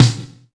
27 DEEP SNR.wav